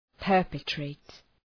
Προφορά
{‘pɜ:rpı,treıt} (Ρήμα) ● διαπράττω